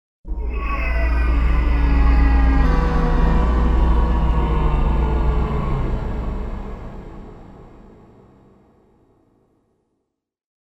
На этой странице собраны пугающие звуки ада: от далеких воплей до навязчивого скрежета.
Адские стоны